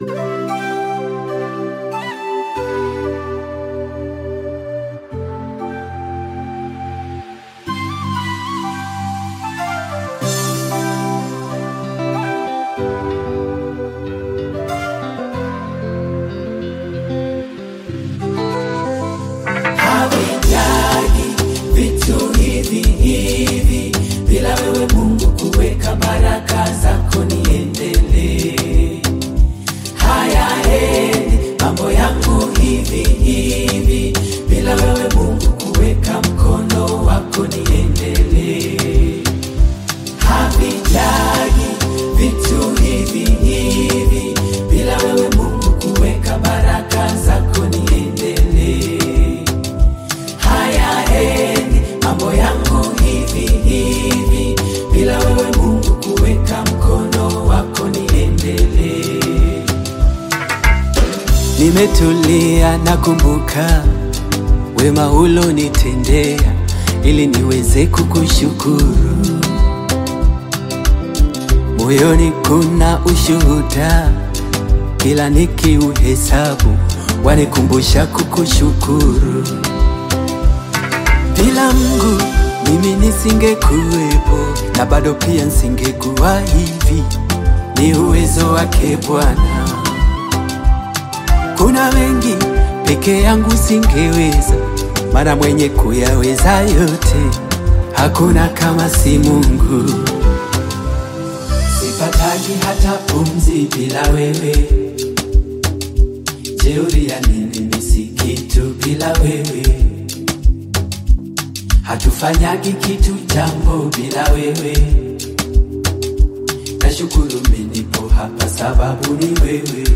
GOSPEL
Celebrated Tanzanian gospel artist
heartfelt delivery